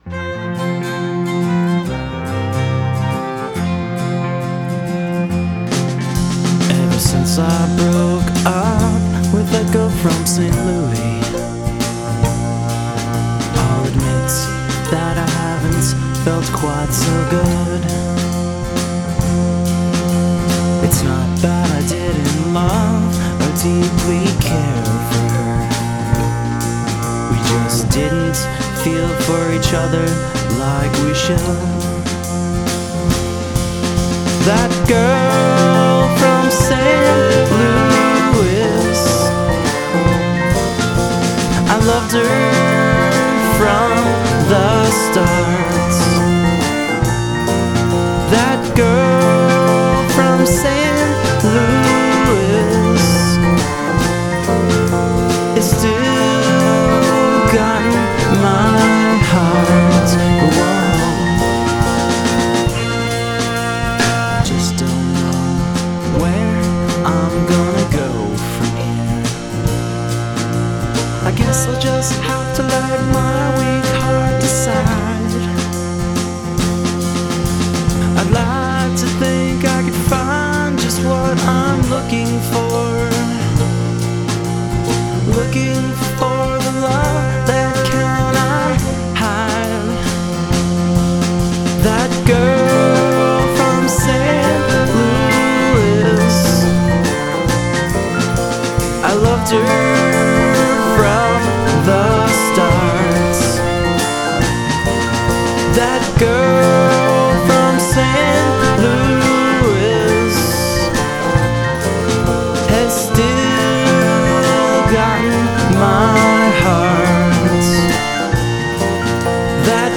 violin
cello
drums